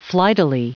Prononciation du mot flightily en anglais (fichier audio)
Prononciation du mot : flightily